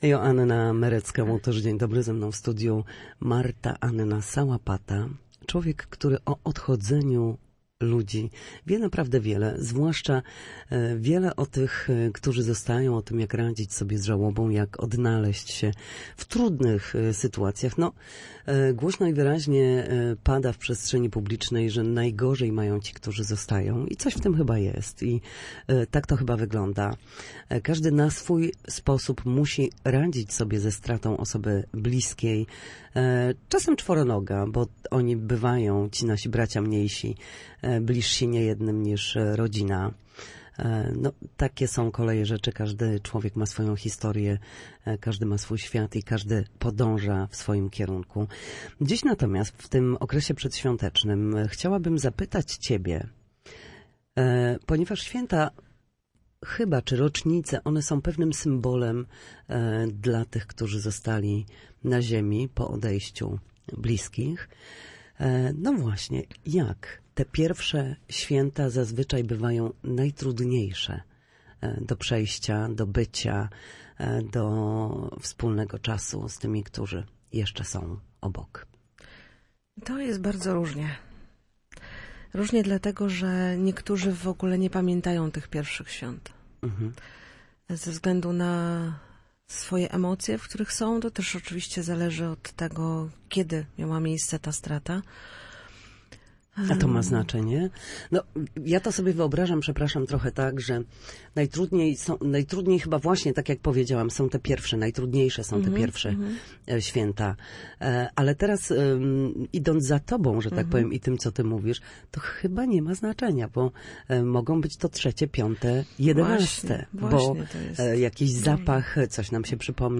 Na antenie Radia Gdańsk mówiła o przeżywaniu świąt w żałobie.